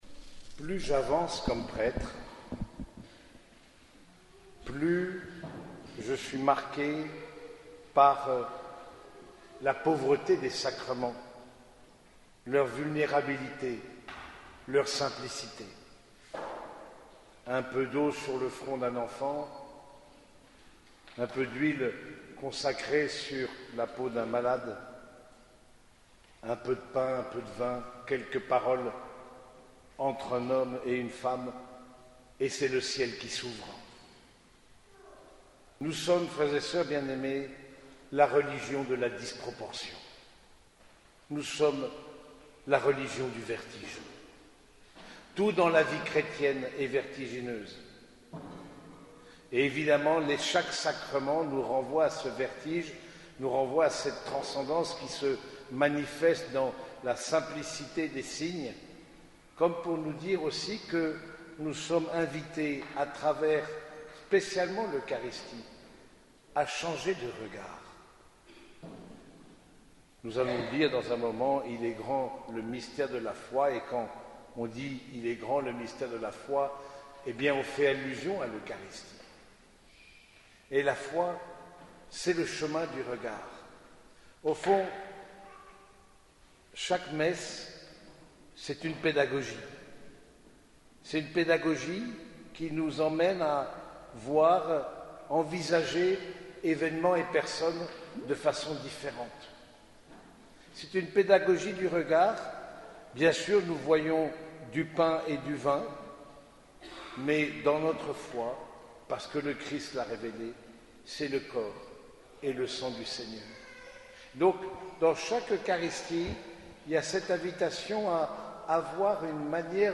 Homélie de la solennité Saint Sacrement du Corps et du Sang du Christ
Cette homélie a été prononcée au cours de la messe dominicale à l’église Saint-Germain de Compiègne.